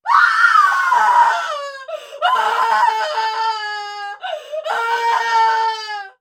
Звуки крика женщины
Ужасающий вопль женского голоса